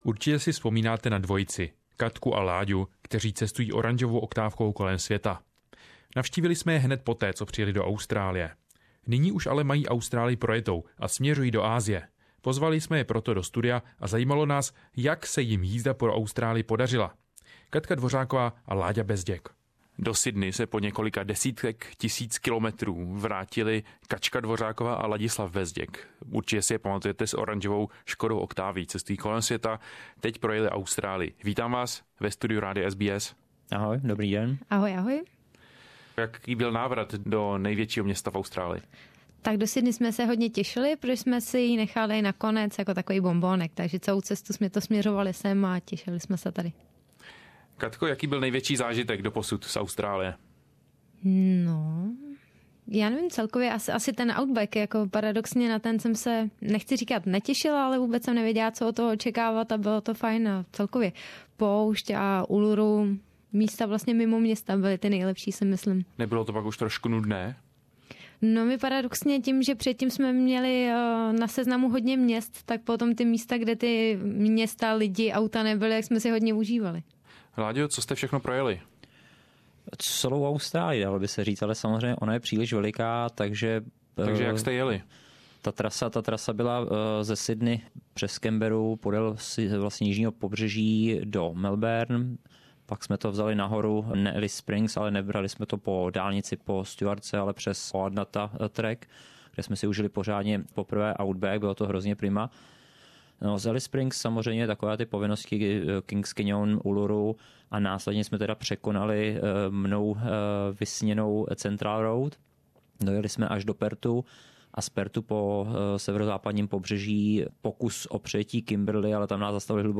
In this first part of our interview they share their Australian episode and they give advice on how to see as much as possible on budget.